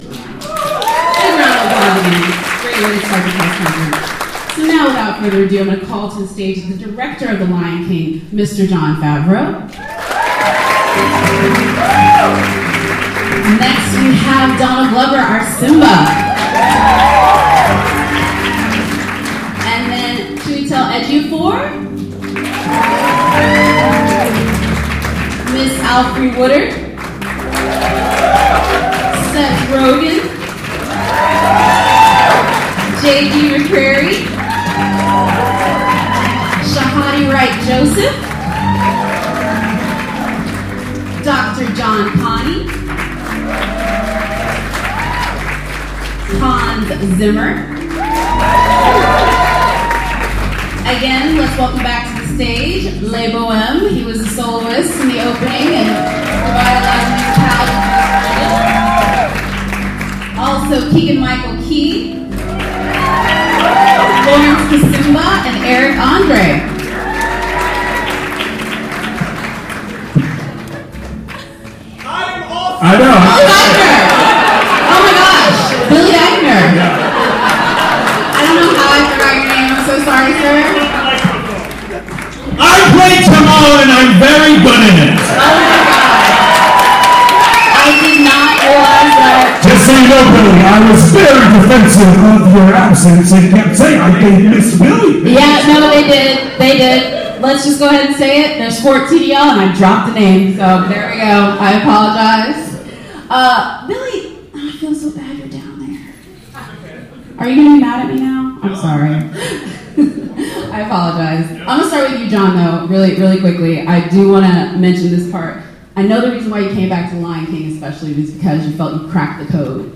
A week ago we sat in on The Lion King global press conference with cast (minus Beyonce) Donald Glover, Chiwetel Ejiofor, Alfre Woodard, Seth Rogen…